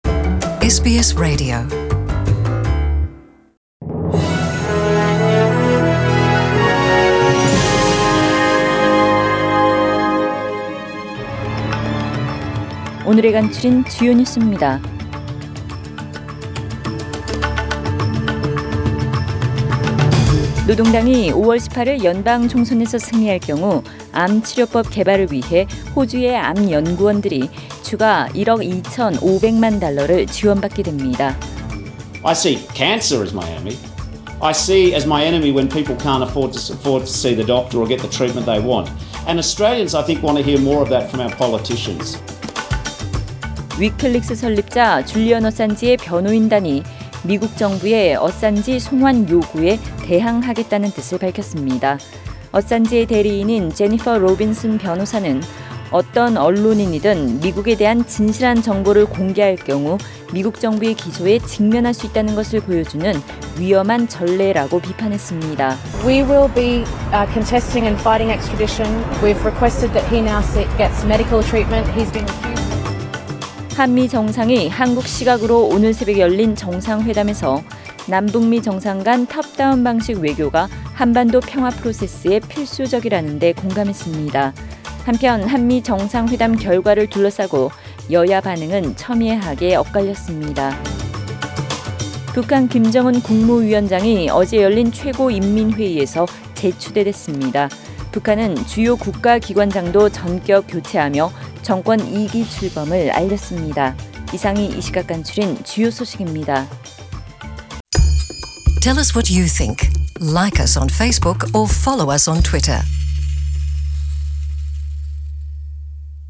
2019년 4월 12일 금요일 저녁의 SBS Radio 한국어 뉴스 간추린 주요 소식을 팟 캐스트를 통해 접하시기 바랍니다.